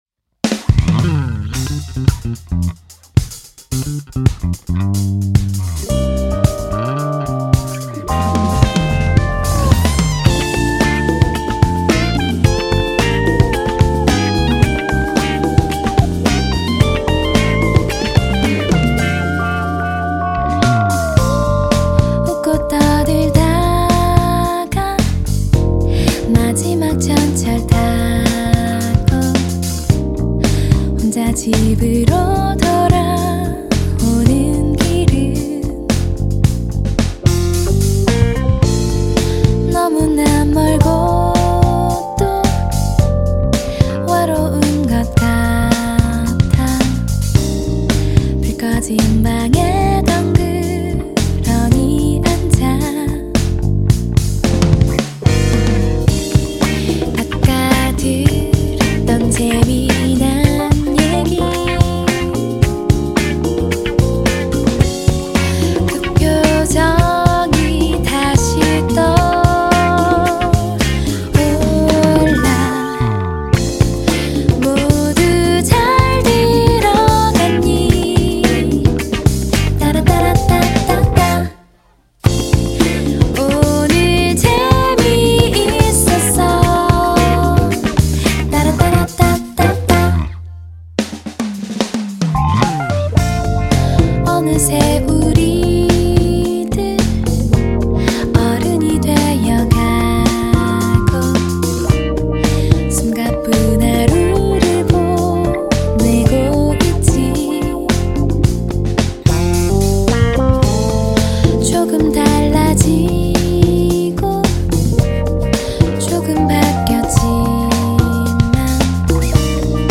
예컨데, 레게이면서 포크 같기도 하고, 훵키인데 동시에 라틴 같기도 한 그런.
그리고, 정규 1집에 비해 어쿠스틱 악기들을 전면에 내세웠고
일렉트릭한 요소들은 잘 안 들리는 위치로 숨겨놨는데
Vocals
Chorus
Guitars
Bass
Percussions